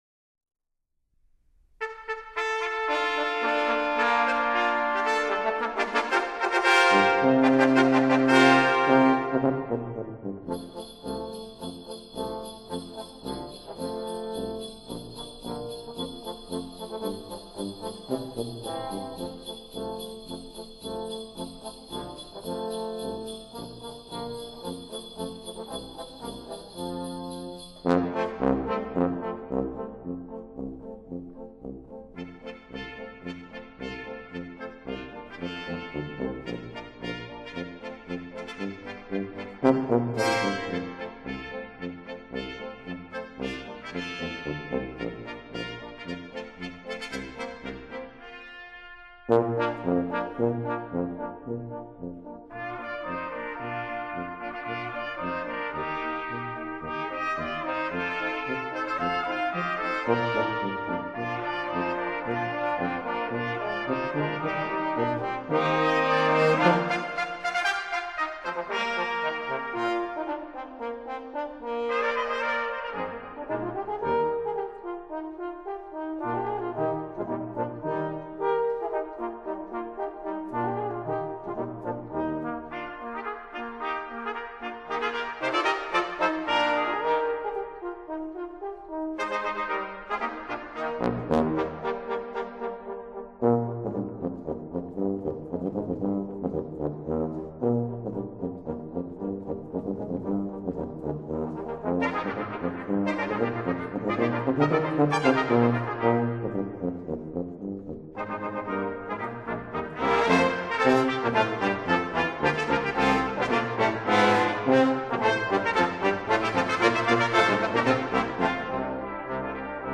SCANS Genre: Vocal, Christmas, Classical